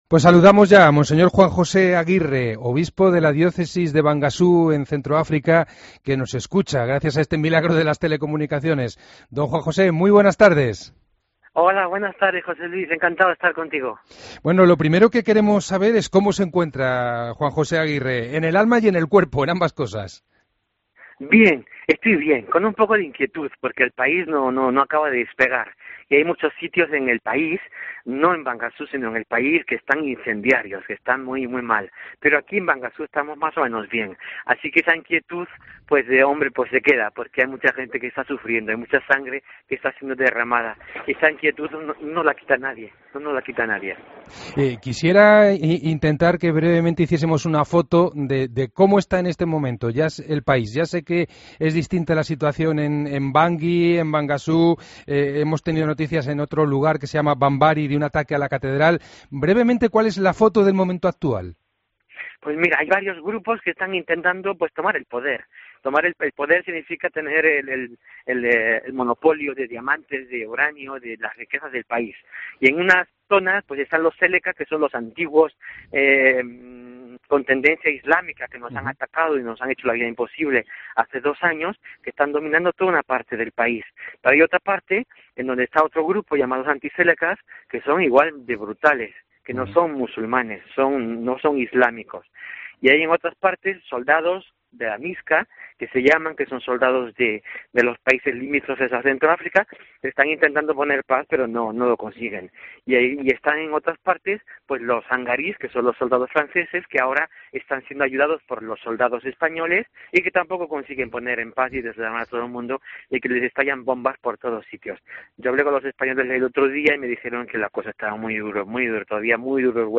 AUDIO: Escucha la entrevista completa a monseñor Juan José Aguirre en 'El Espejo'